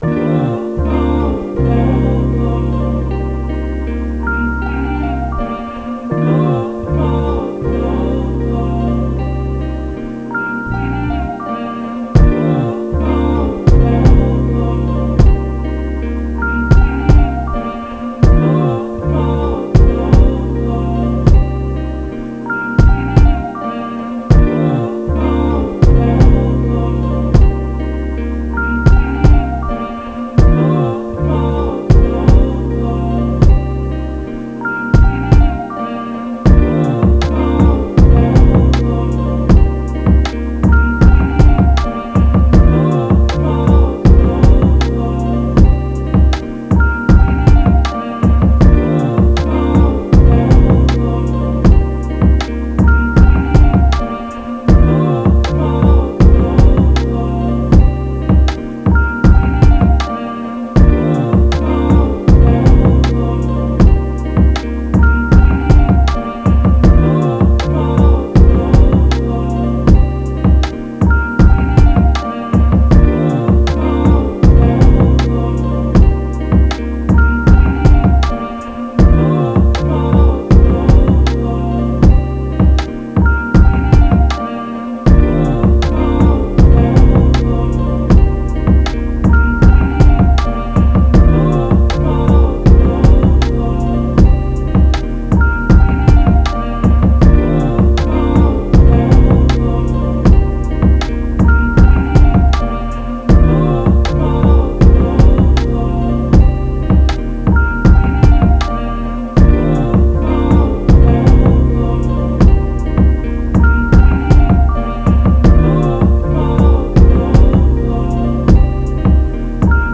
How I feel Official Instrumental.wav